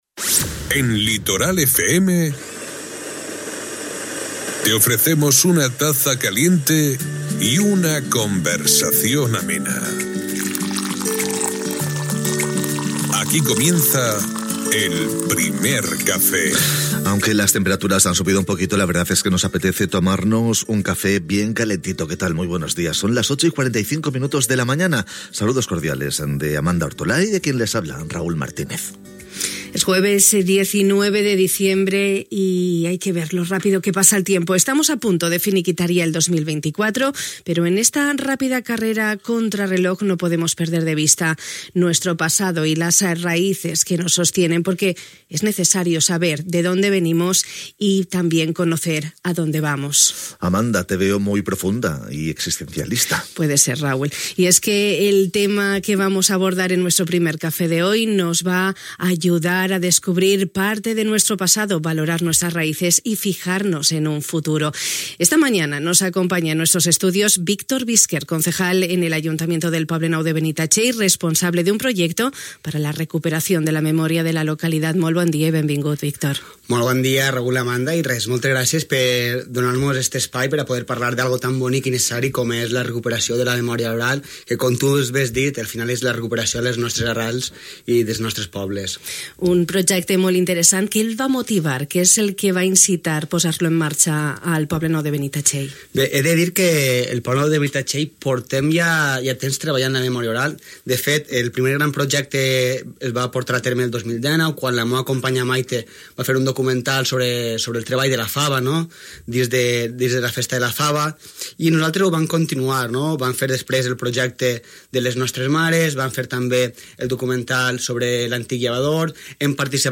El Poble Nou de Benitatxell es troba immers en un projecte per a la recuperació de la memòria, de les seves arrels i història pròpia, a través de la transmissió oral. El Primer Cafè de Ràdio Litoral, amb el regidor responsable, Víctor Bisquert.